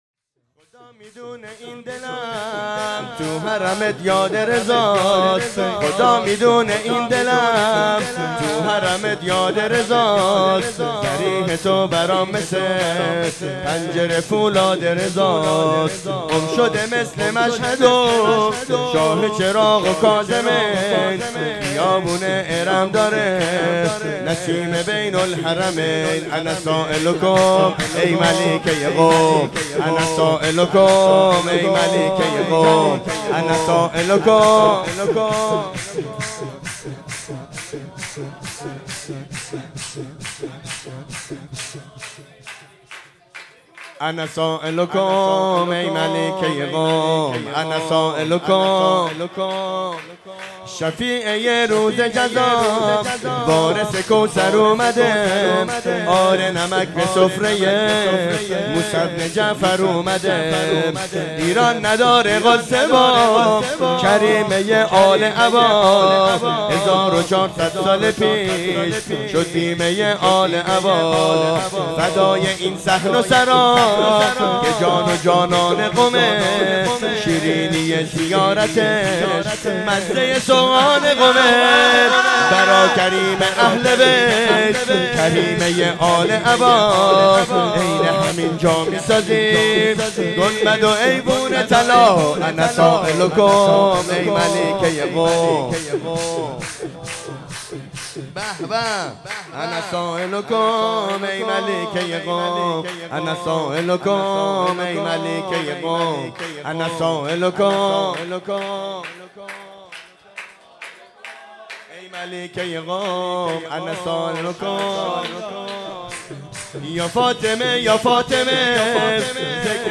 جشن میلاد امام رضا علیه السلام 1404